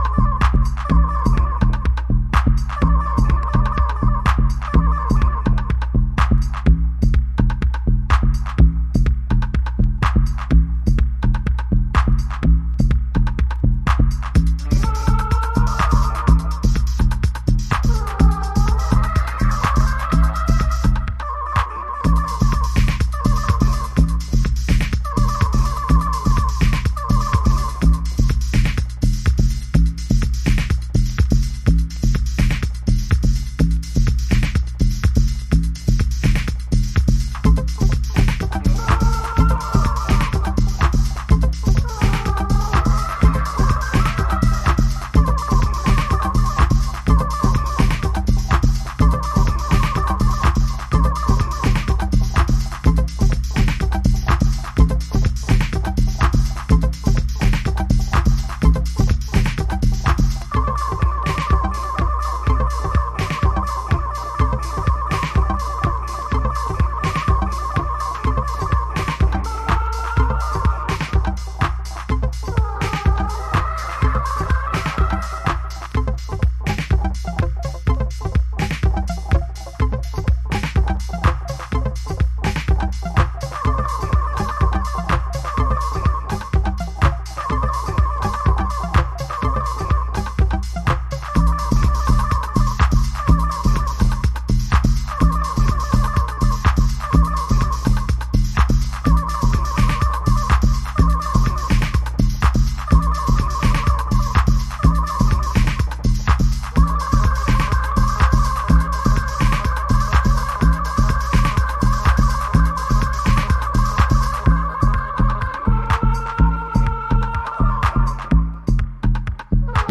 あらゆるヴィンテージ機材を駆使して、80年代後期のシカゴハウスにアプローチ。